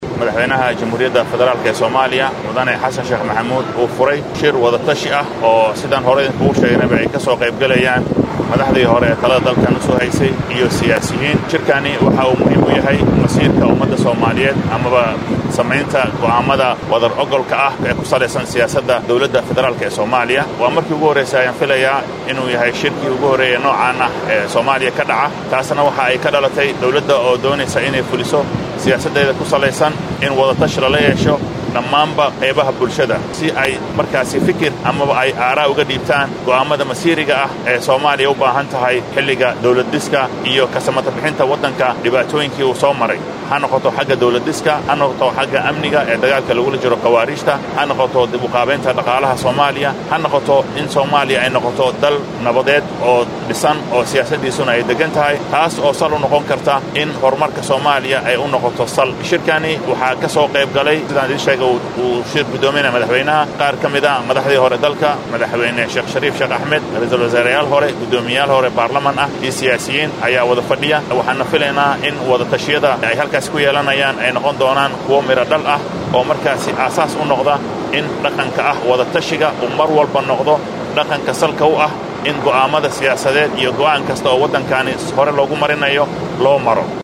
Shirkan ayaa lagu gorfaynaya xaaladda wadanka Soomaaliya, sida amniga, ciribtirka kooxda Al-Shabaab, dib u eegista dastuurka, dimuqraadiyeynta iyo doorashooyinka. Waxaa sidoo kale qodobbada shirkan diiradda lagu saarayo faahfaahiyay wasiirka wasaraadda warfaahinta Soomaaliya Daa’uud Aweys.
Wasiirka-warfaafinta-Soomaaliya.mp3